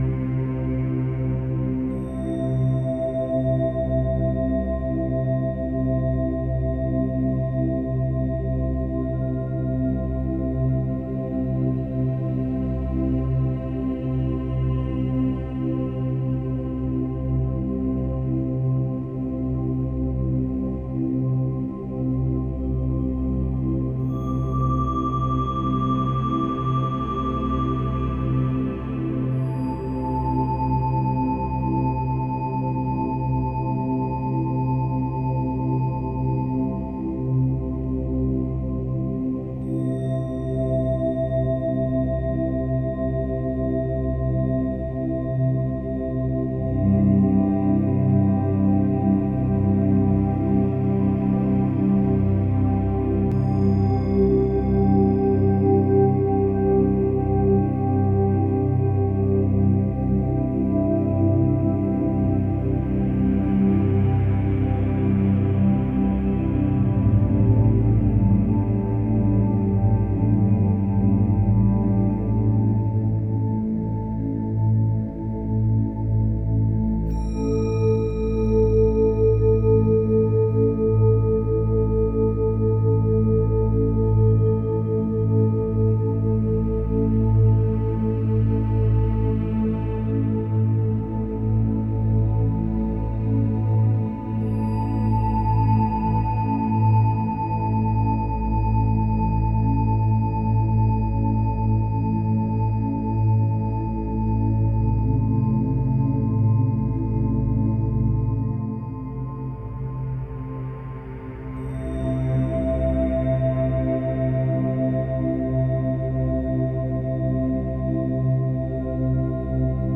meadow.ogg